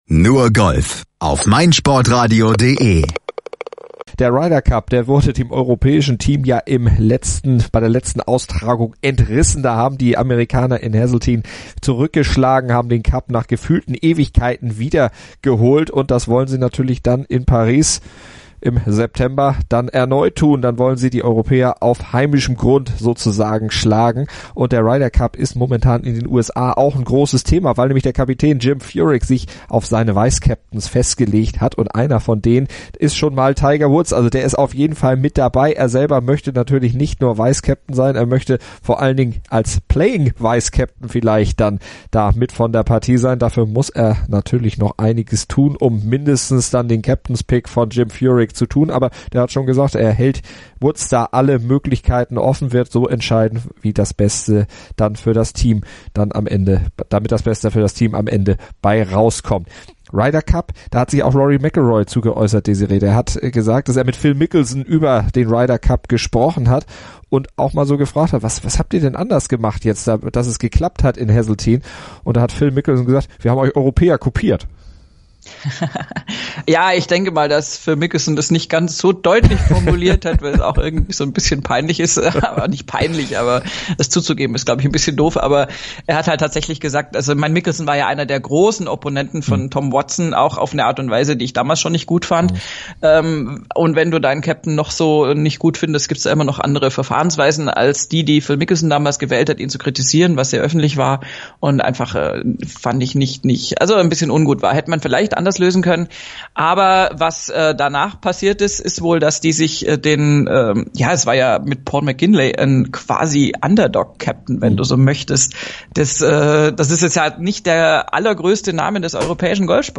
diskutieren darüber